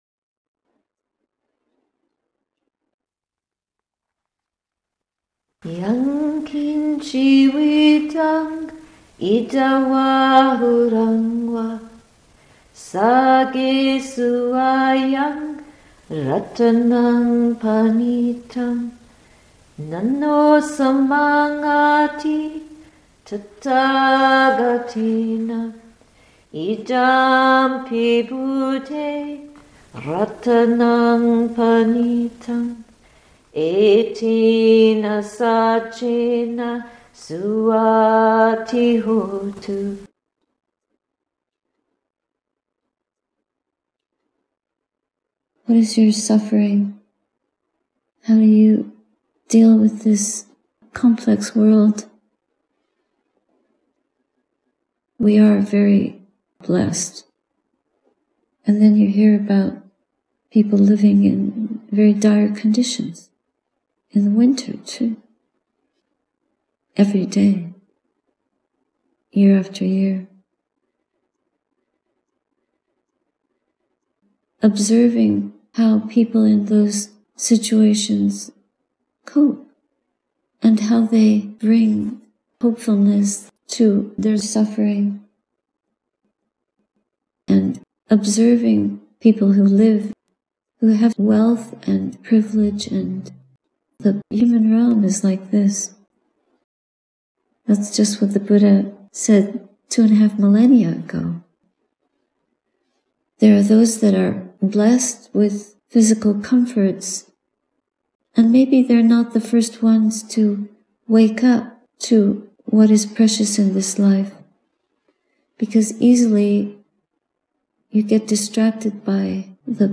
Sati Saraniya Hermitage, Nov. 2, 2025 https